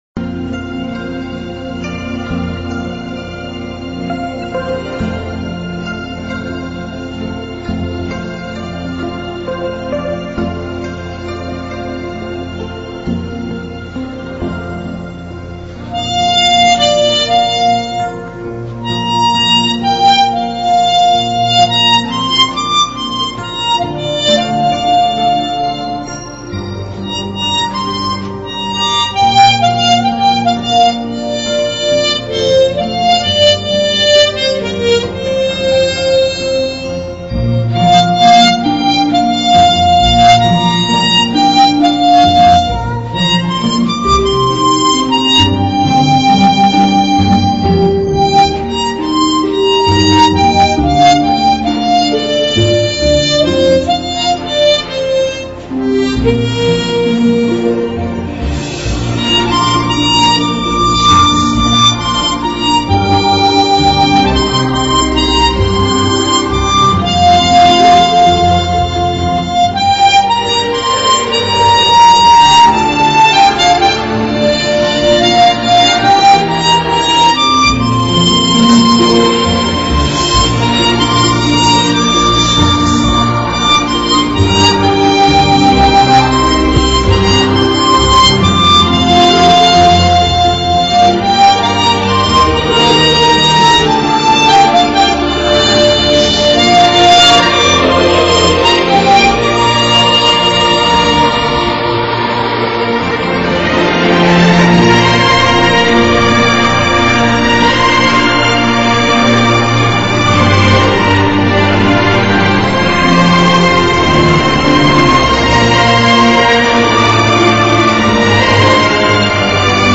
在网上找到一个网友用口琴演奏的此曲